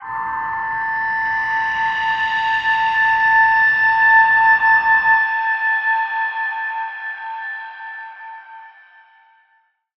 G_Crystal-A7-mf.wav